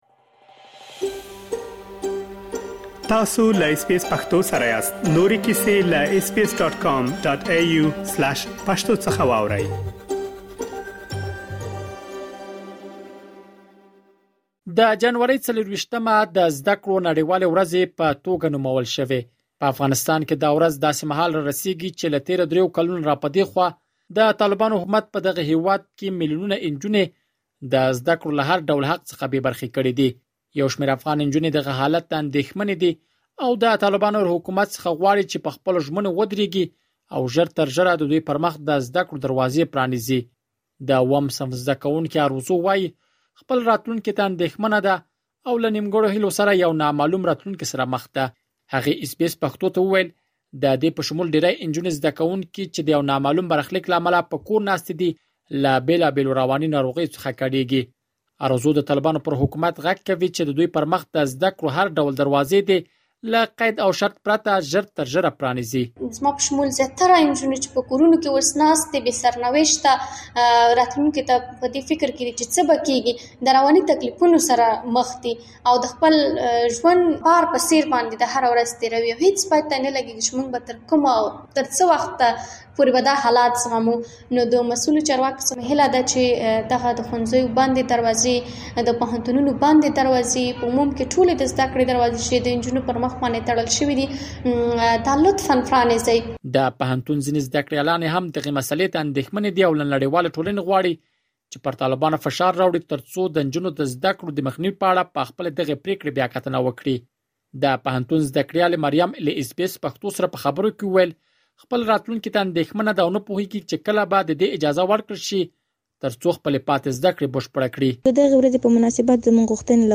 مهرباني وکړئ په دې اړه لا ډېر معلومات له کابل څخه په را استول شوي رپوټ کې واورئ.